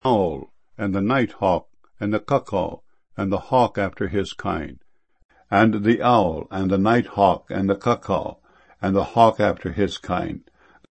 cuckoo.mp3